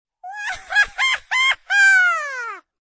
Descarga de Sonidos mp3 Gratis: risa 9.